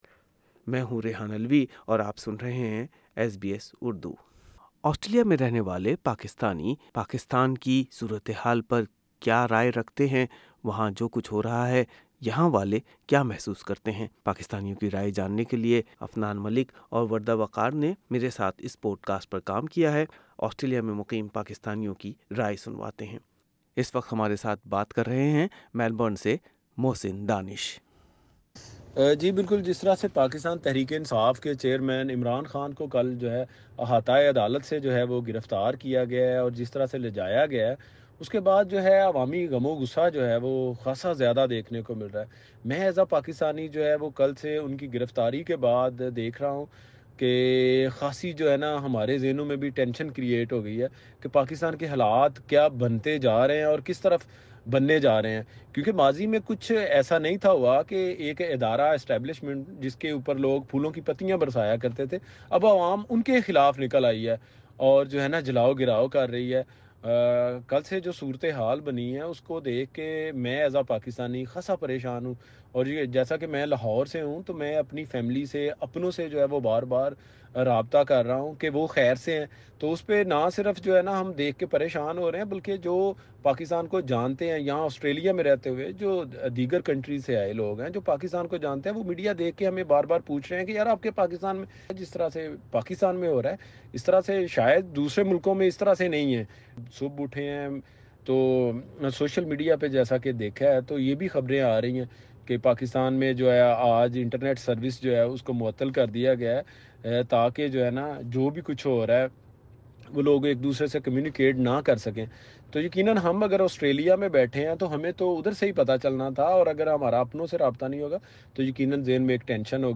اس پوڈ کاسٹ میں عمران خان کی گرفتاری کے خلاف احتجاجی مظاہرے کے شرکا اور عمران خان کی گرفتاری اور ملکی صورتحال پر آسٹریلیا میں مقیم کچھ پاکستانیوں کی رائے شامل ہے۔
The protest rally in Sydney is organized by Pakistan Tehreek-e-Insaf Australia (Official)